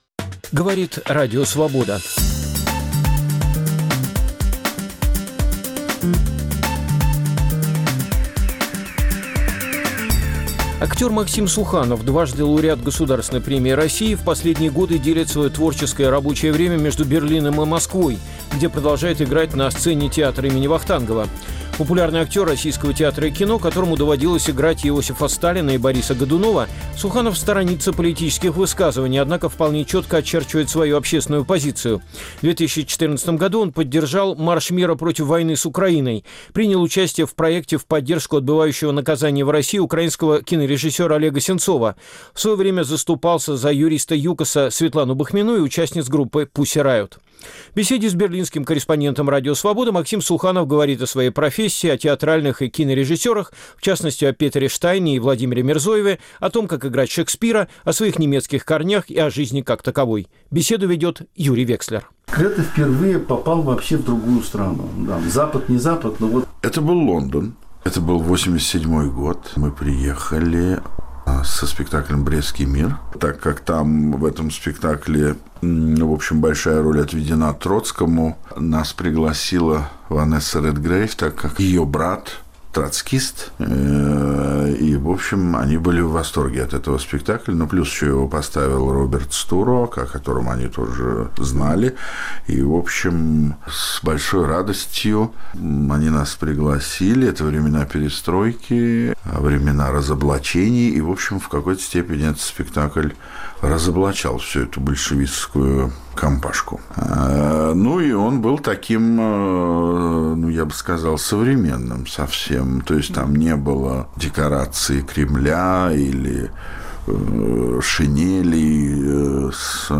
Берлинская беседа